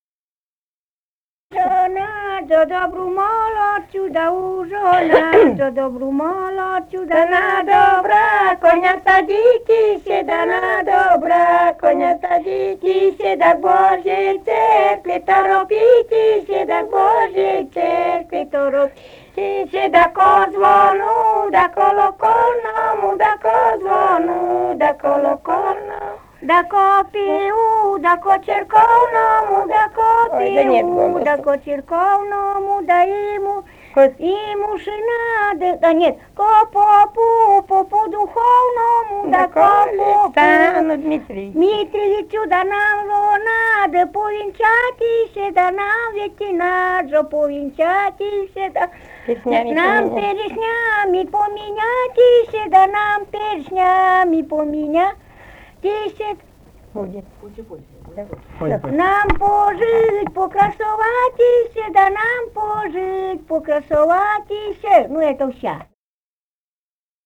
Живые голоса прошлого [[Описание файла::149. «[У]жо надо добру молодцу» (свадебная).